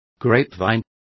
Complete with pronunciation of the translation of grapevine.